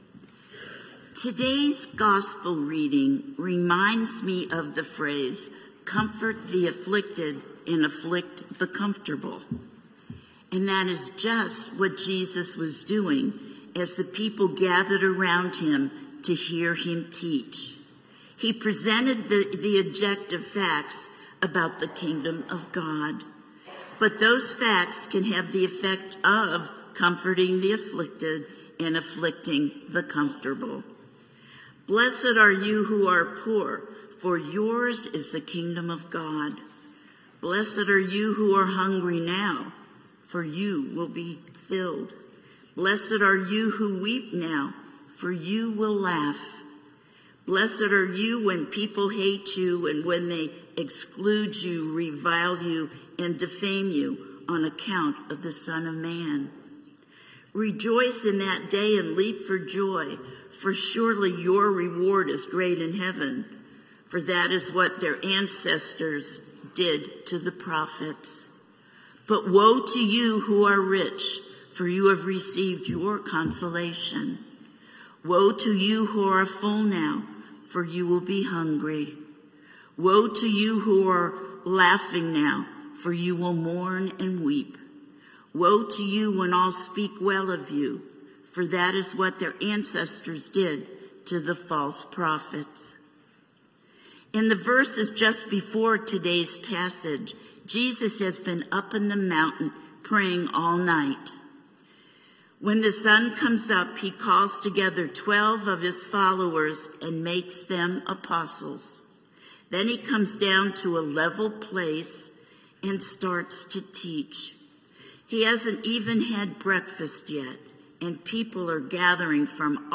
St-Pauls-HEII-9a-Homily-16FEB25.mp3